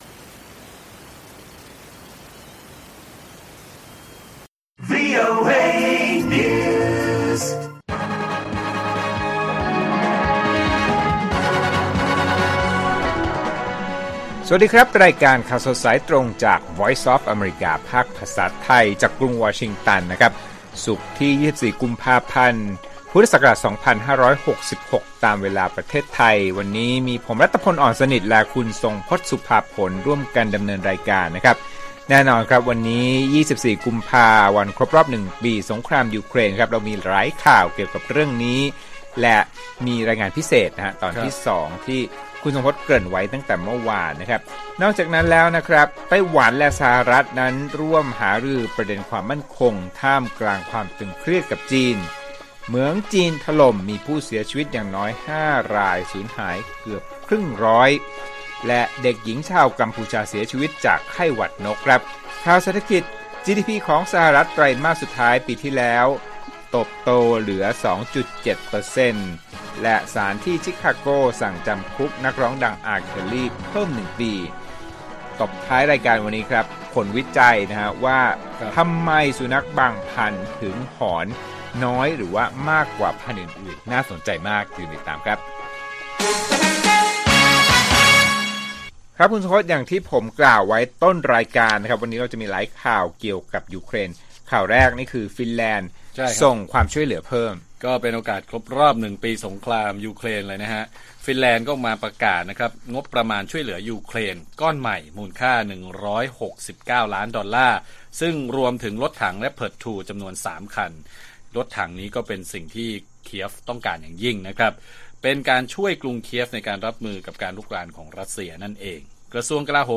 ข่าวสดสายตรงจากวีโอเอไทย 6:30 – 7:00 น. วันที่ 24 ก.พ. 2566